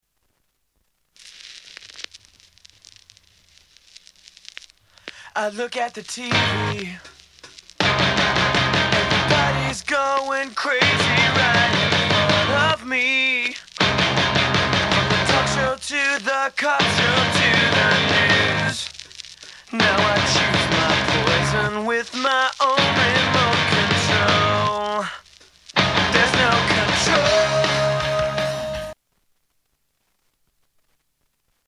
STYLE: Rock
It does sound sort of '80s retro...